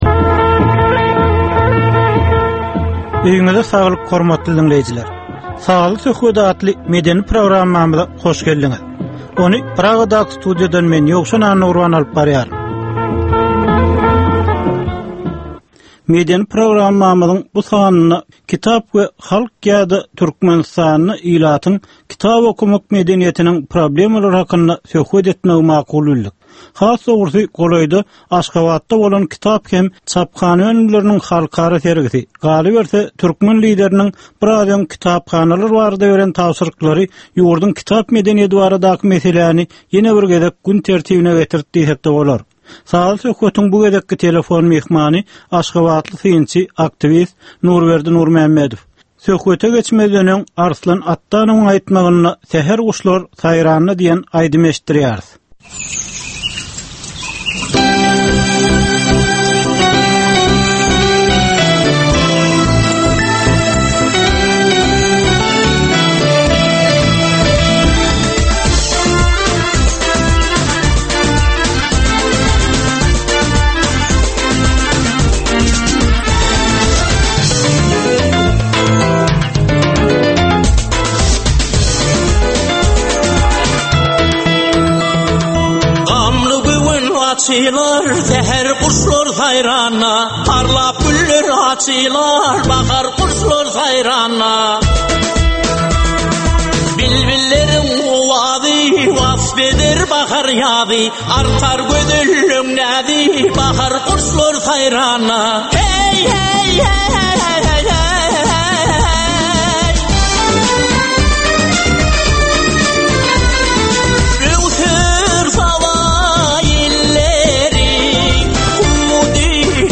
Türkmeniň käbir aktual meseleleri barada sazly-informasion programma.